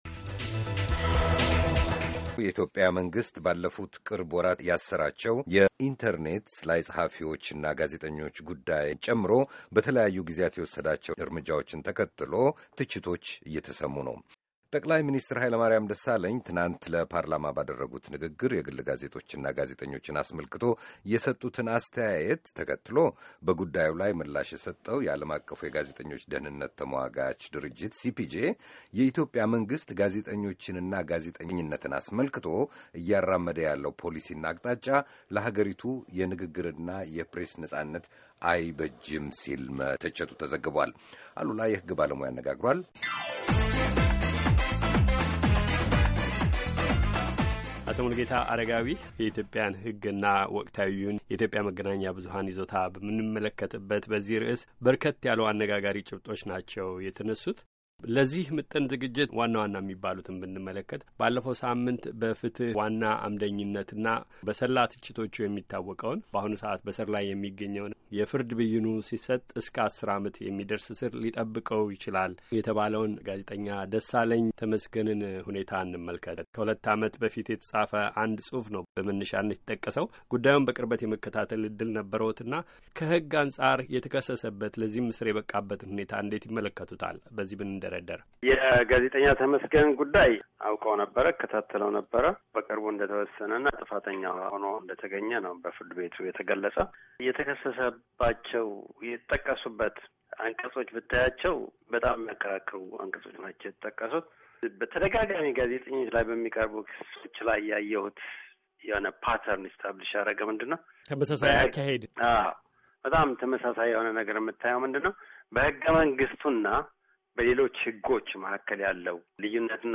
በሰሞንኛው የመንግስቱ እርምጃዎችና በንግግር ነጻነት መብት ላይ አንድምታ ባላቸው የተለያዩ የአገሪቱ ሕጎች ዙሪያ ከኅግ ባለሞያ ጋር የተካሄዱ ተከታታይ ቃለ ምልልሶች ከዚህ ያድምጡ።